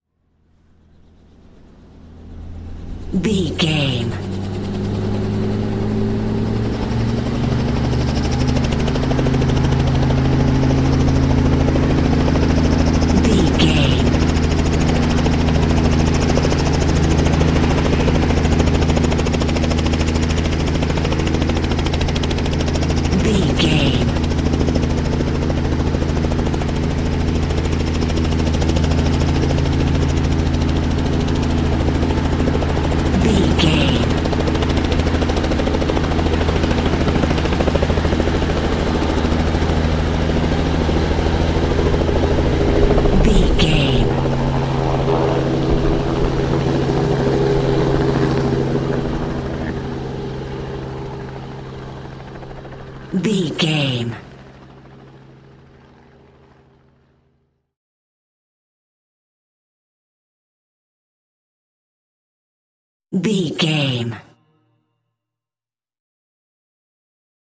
Stereo hi def audio of small helicopter take off from field. Idle to take off sound.
Chopper Take Off
Sound Effects
helicopter
chopper-take48741.mp3